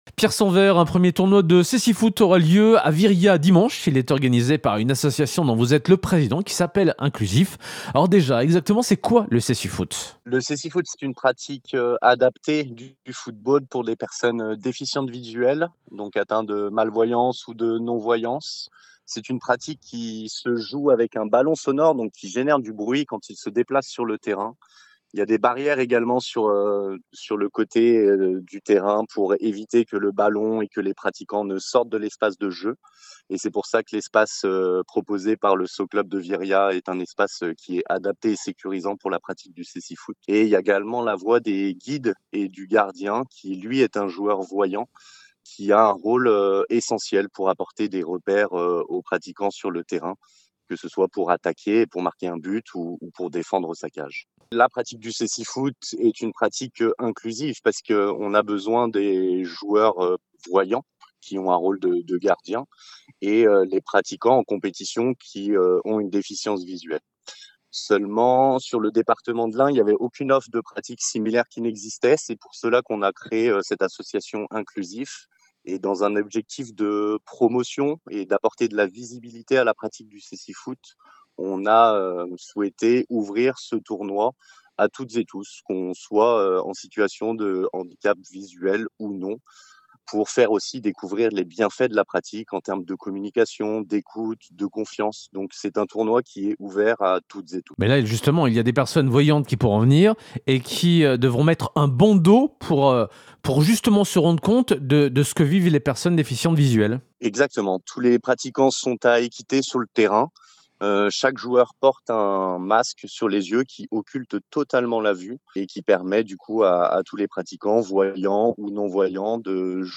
3. Interview de la Rédaction